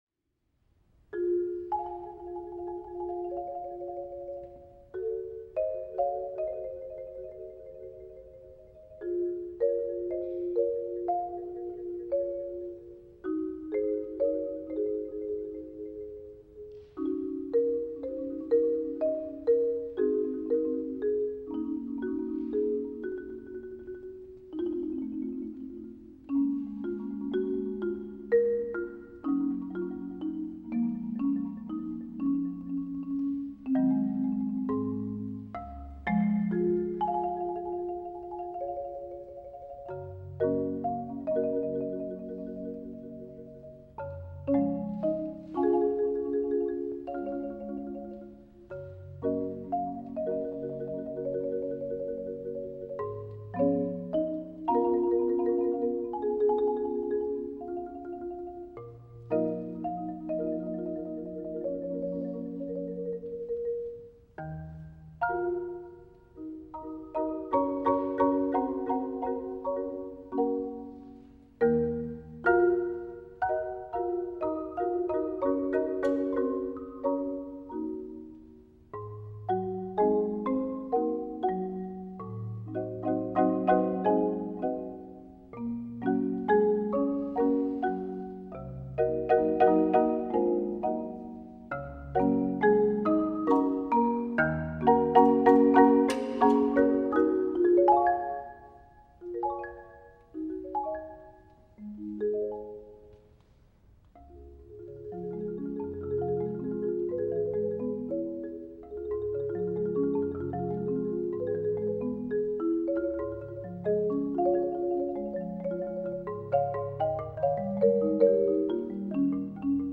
Voicing: Marimba Duet